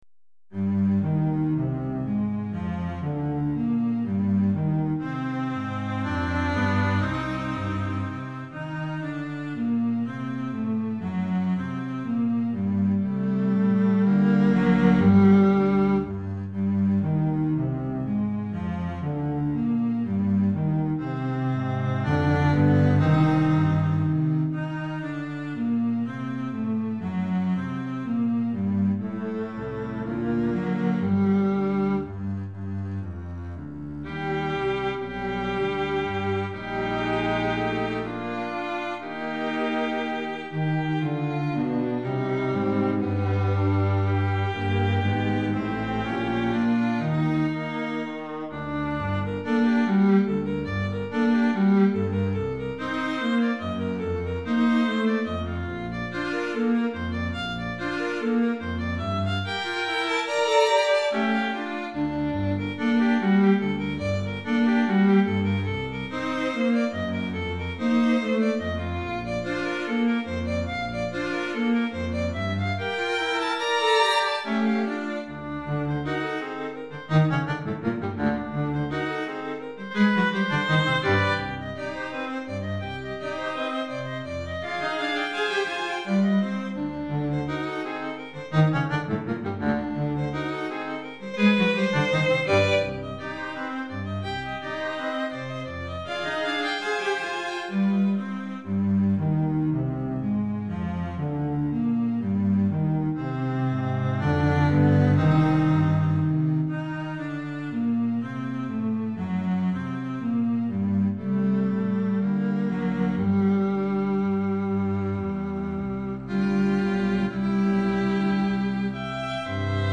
2 Violons, Alto, Violoncelle et Contrebasse